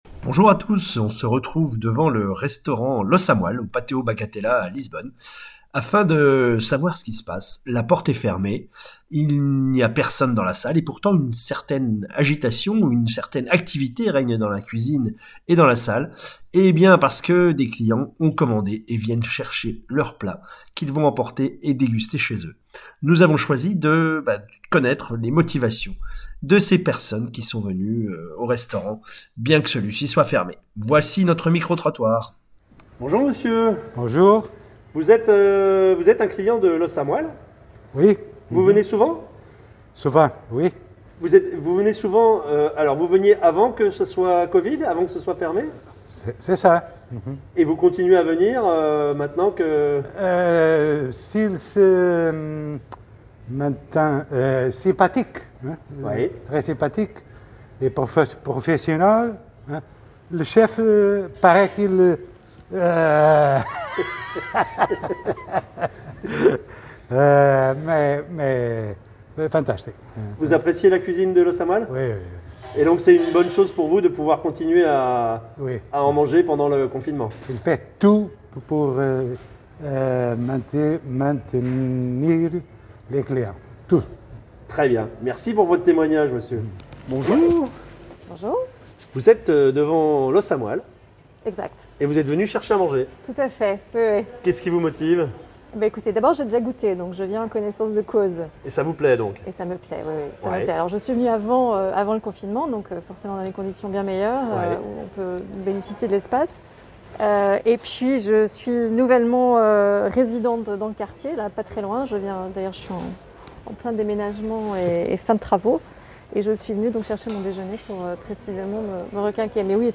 Micro-Trottoir devant l’Os A Moelle : Qui sont ceux qui continuent à manger au restaurant ?
Nous rencontrons des clients portugais, français et même canadiens !